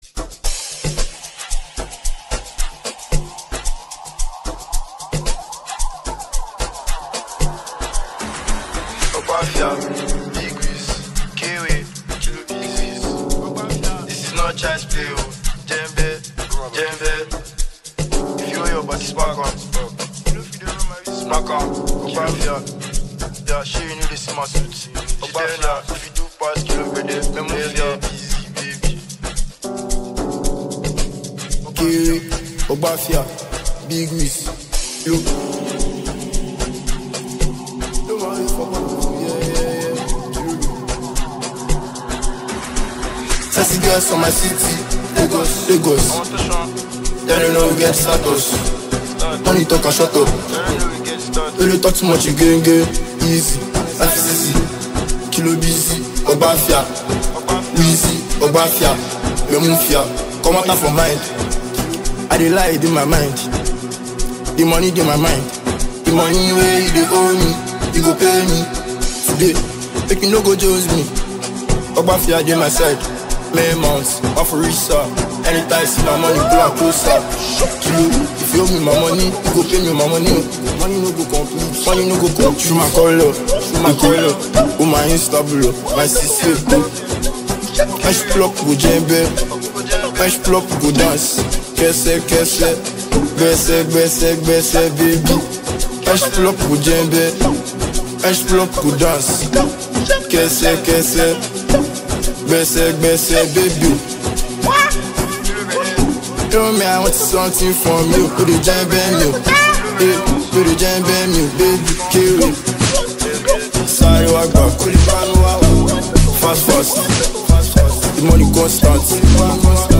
fresh upbeat banger
one rich fusion of the Afrobeats and Afrohouse